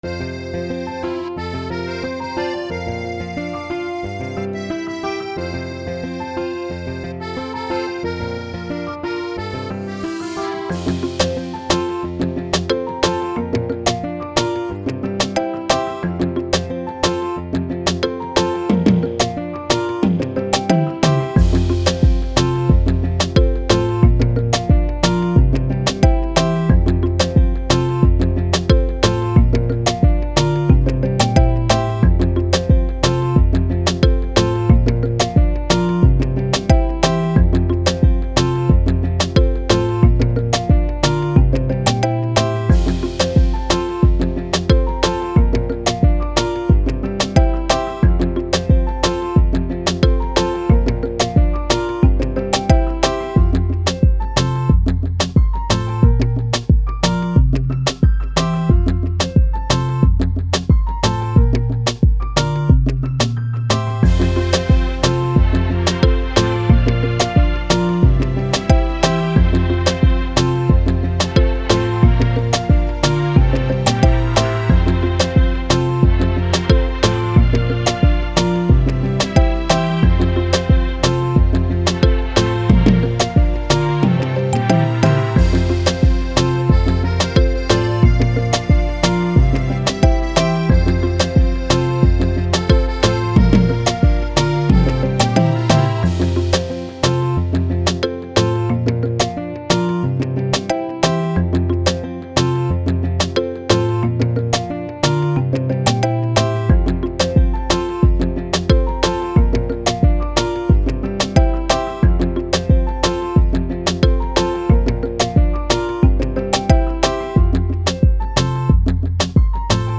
Attachments Puzo Afro.mp3 Puzo Afro.mp3 7.2 MB